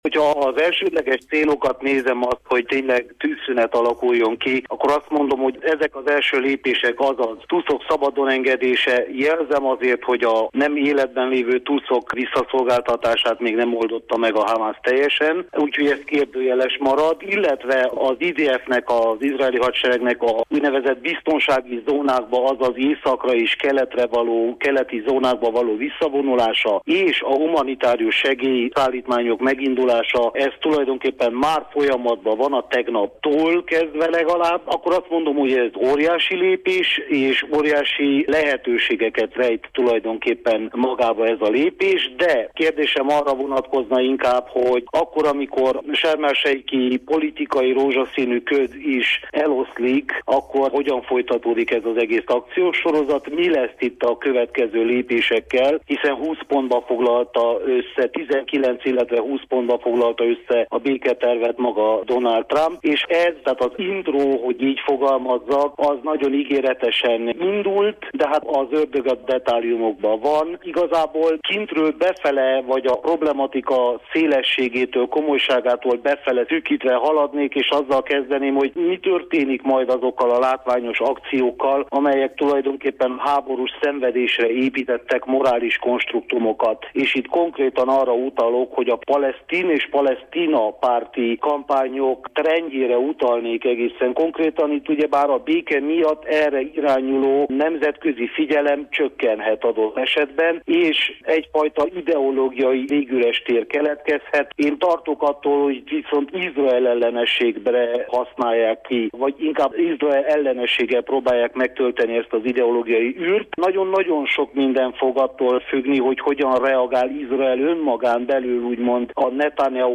külpolitikai elemzőt